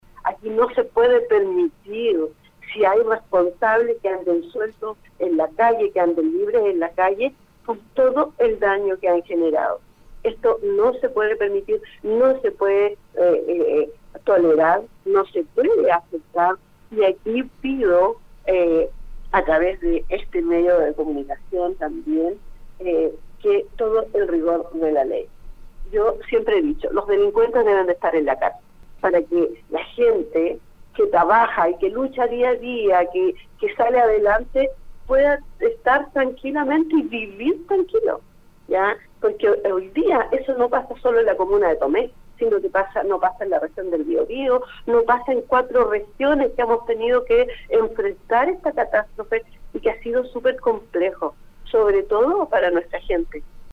Evaluando el escenario que actualmente enfrenta su comuna, Ivonne Rivas, alcaldesa de Tomé, conversó con Radio UdeC para describir la actual fase de emergencia en la que se encuentran hoy.